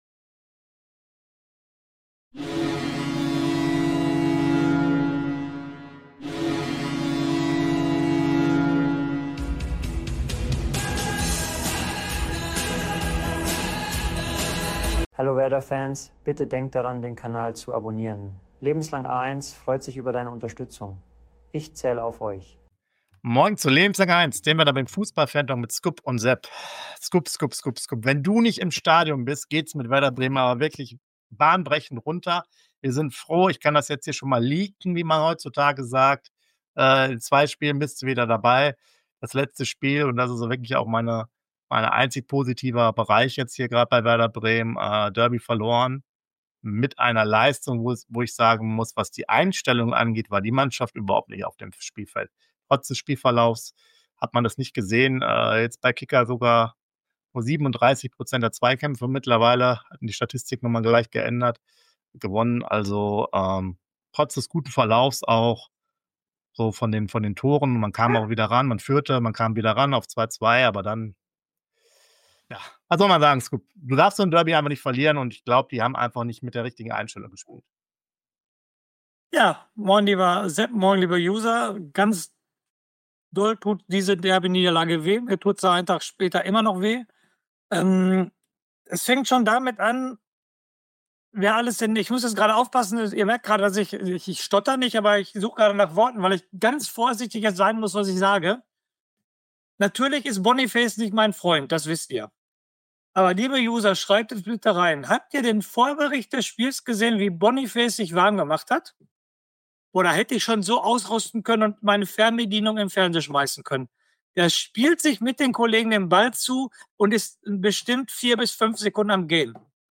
WERDER BREMEN FUßBALL FANTALK!!! Hier gibt es Infos, News und heiße Diskussionen vor, zwischen und nach den Spieltagen zu unserem Verein.